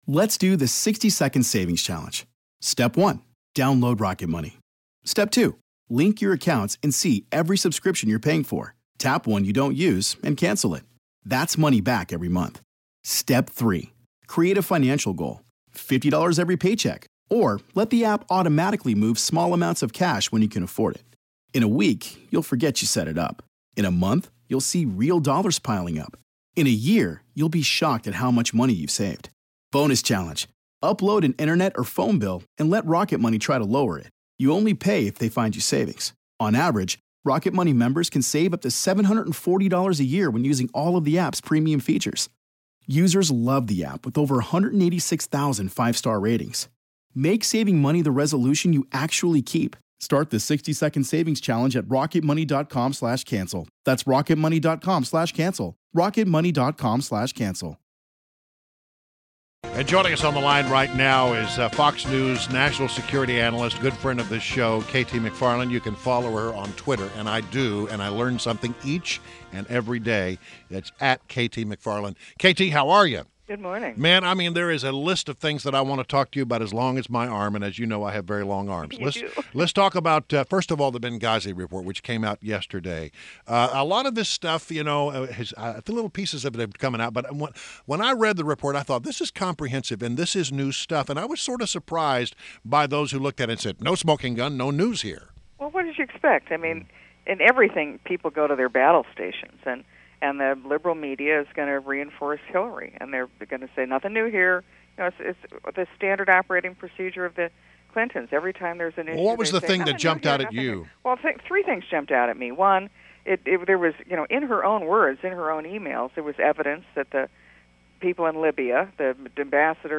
WMAL Interview - KT McFarland - 06.29.16